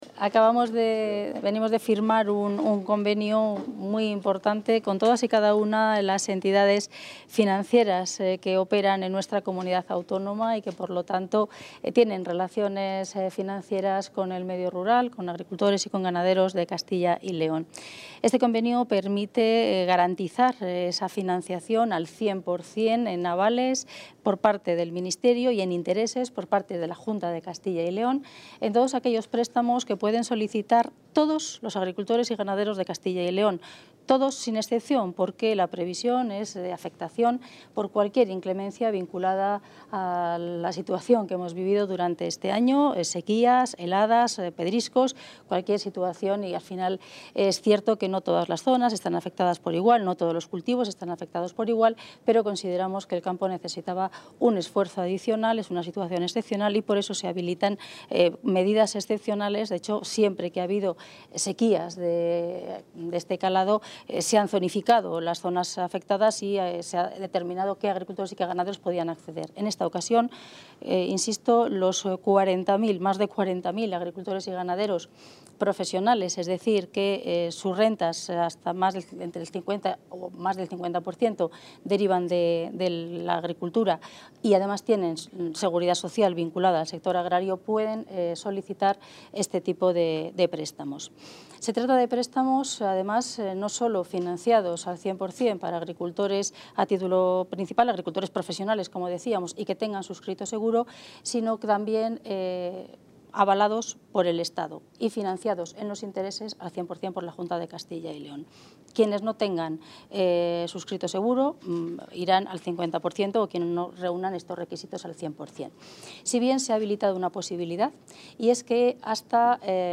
Audio consejera de Agricultura y Ganadería.